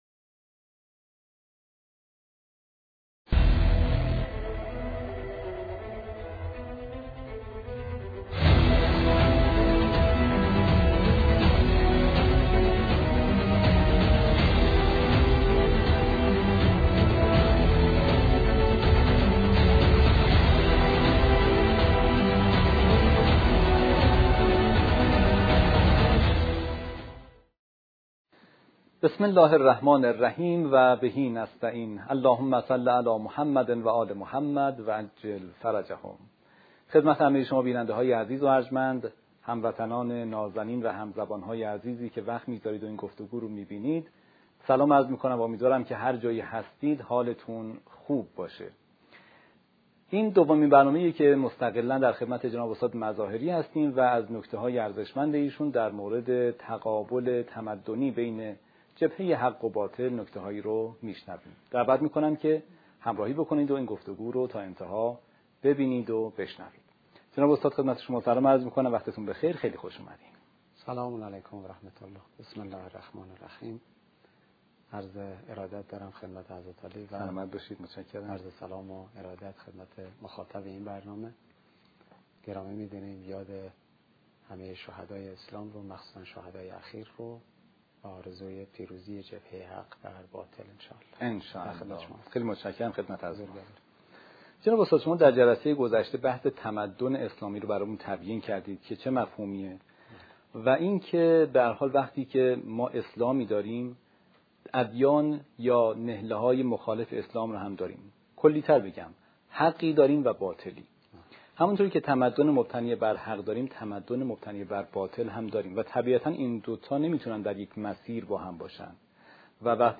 این دومین گفتگوی اختصاصی در این موضوع است و مخاطبان به همراهی تا پایان دعوت شده‌اند.